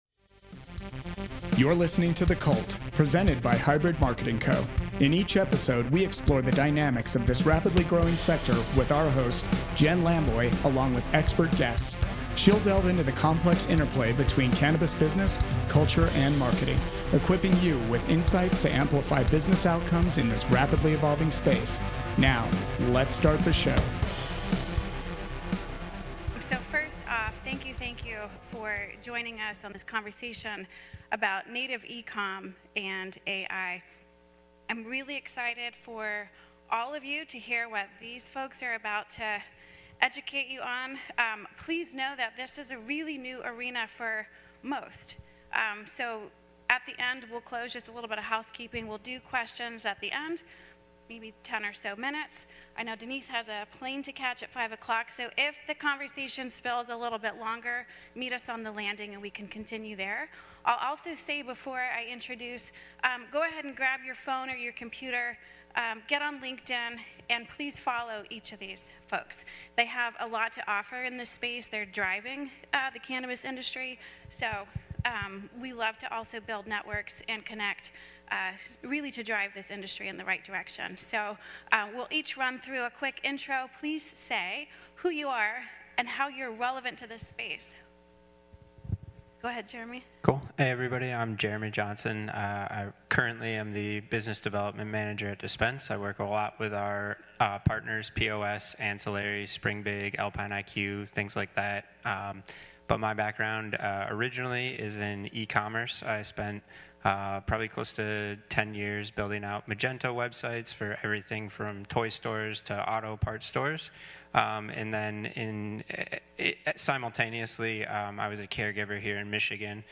Taken from the conference session at MJ Unpacked Detroit, Oct. 10-12, 2023, at the MotorCity Casino Hotel.